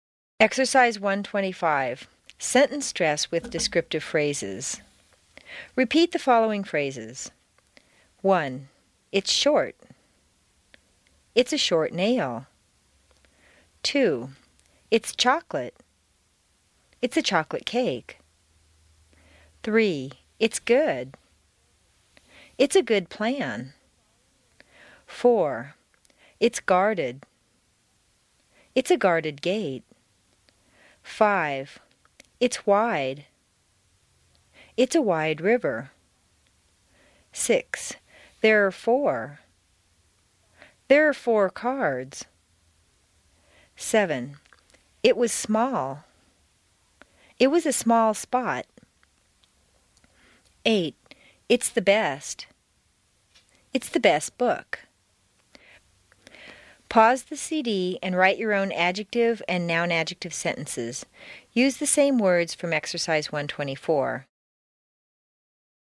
Exercise 1-25: Sentence Stress with Descriptive Phrases CD 1 Track 37